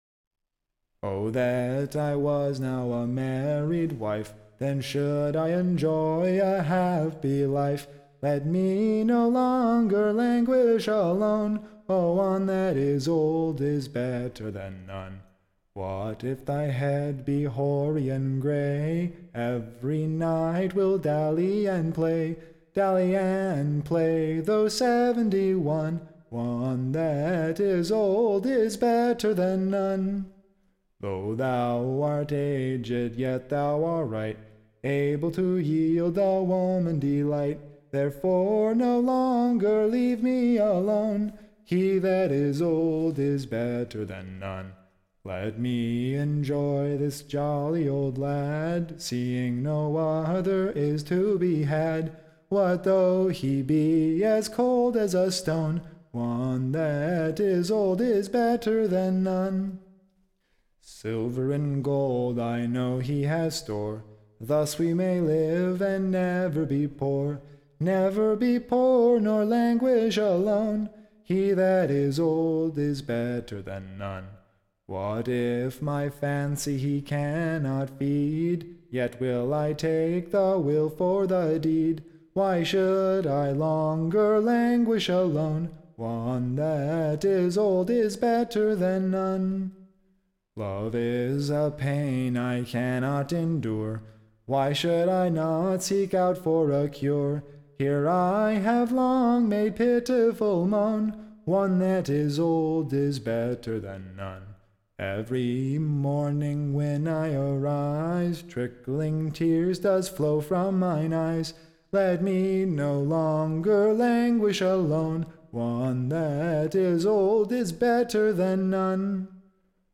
Recording Information Ballad Title An Answer to the / Old Man's WISH: / Containing the Young Lasses Longing desire to be Marry'd / and eke to an Old Man rather than fail. Tune Imprint To the Tune of, The Old Mans Wish.